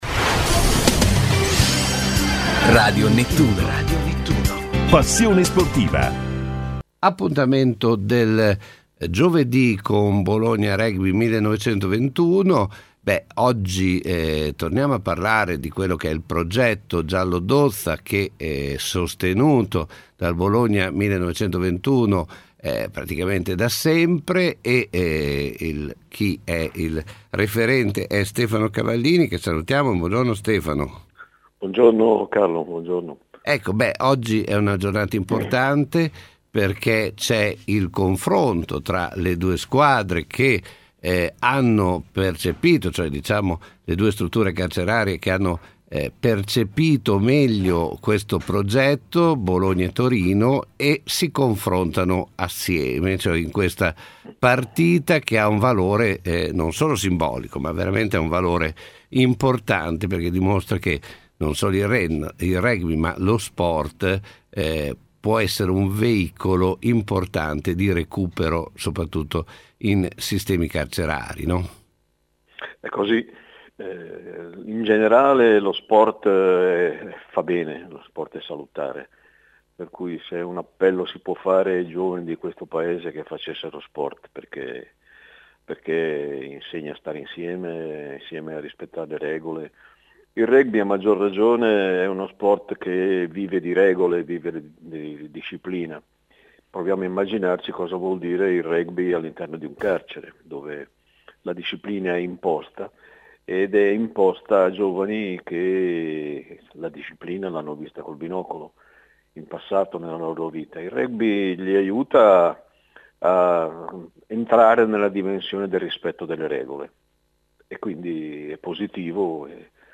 Intervista su Radio Nettuno